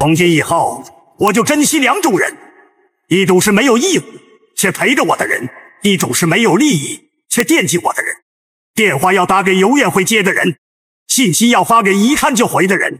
用于戏剧性故事讲述和表演的情感独白声音
用专为激烈独白、诗歌朗诵和电影旁白设计的人工智能声音，捕捉人类情感的深度。
文本转语音
戏剧性表演
独白合成